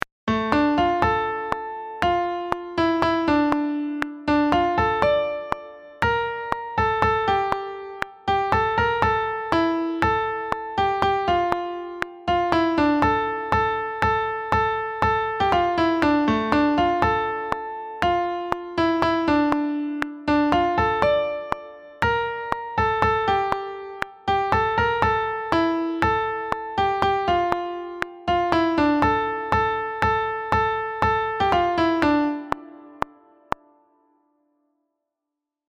sopranes-mp3 15 mai 2022